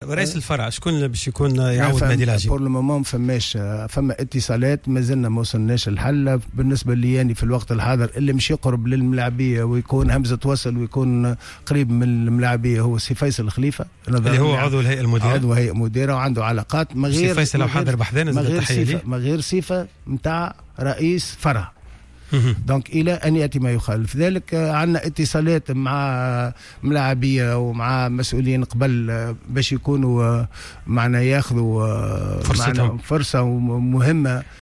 في حوار خاص اليوم على الجوهرة اف ام